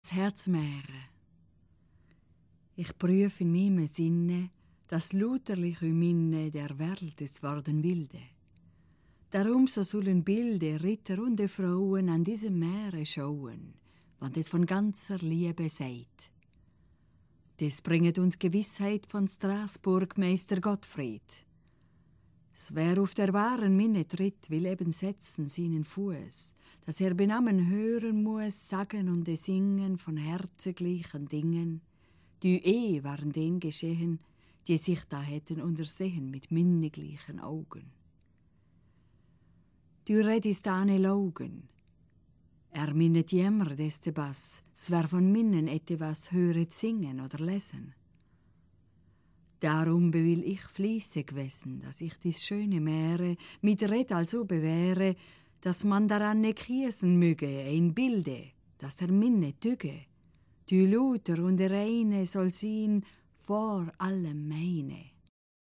Wir erzählten die Maere neu, zweisprachig und angereichert mit Liedern und Instrumental-Improvisationen.
Im Begleitprogramm der Ausstellung zur Geschichte des Herzens im Museum Appenzell (2004)
Hackbrett